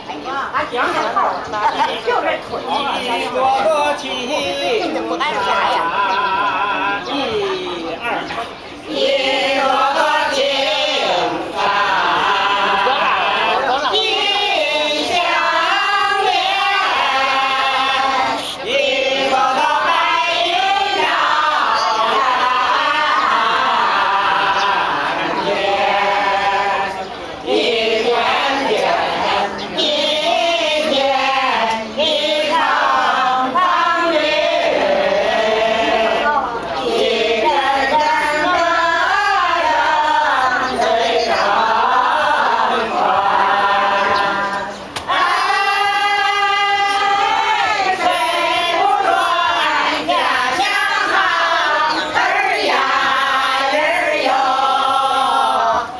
click picture for enlarged view   Singing
Out by the lake, under the awning, there was a group of about 16 elderly people singing. There was a leader, who selected the songs and offered encouragement and/or instruction to the others. Everyone was over 50, some looked to be in their 80's. I wondered what songs these were, why they were singing them here, and why there were no young people with them.